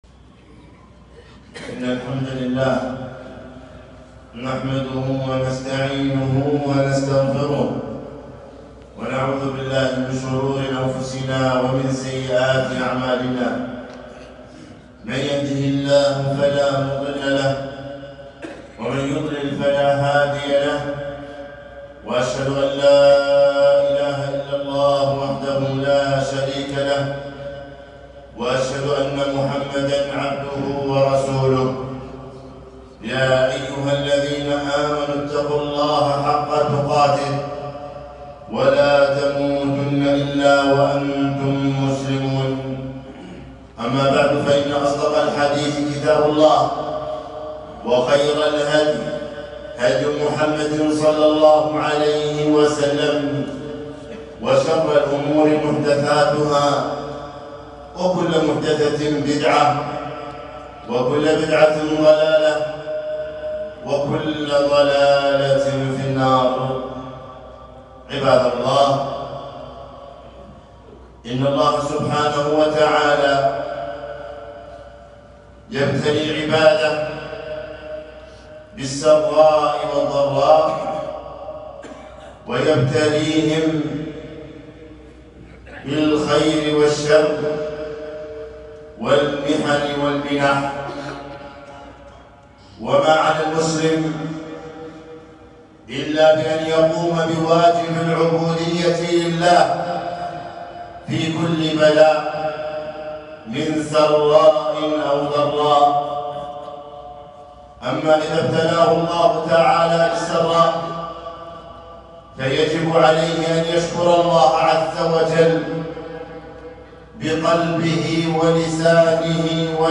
خطبة - ولا تقتلوا أنفسكم - دروس الكويت